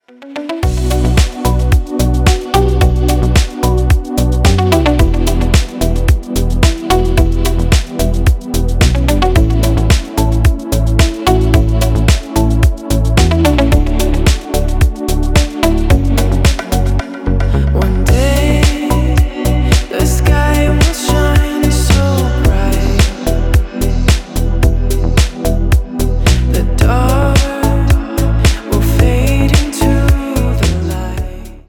Громкая танцевальная песня доступна бесплатно.